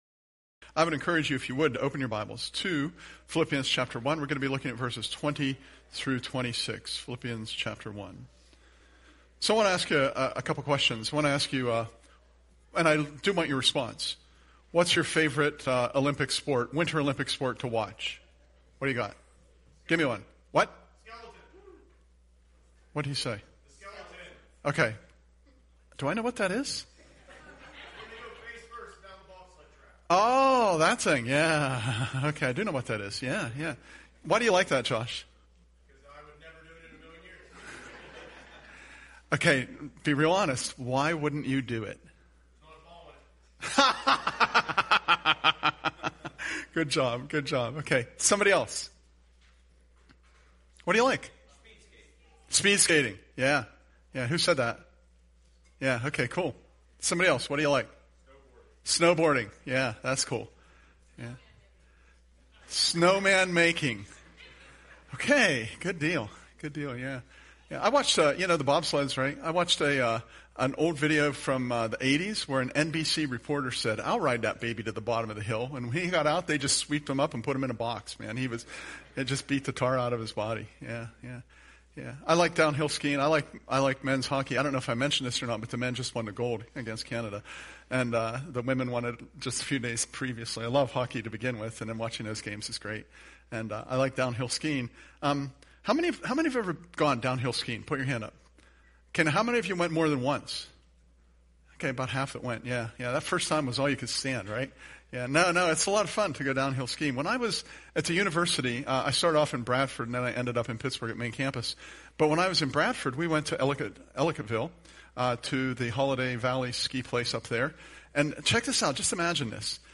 Living a Life of Courage :: PHILIPPIANS – Curwensville Alliance Church Podcasts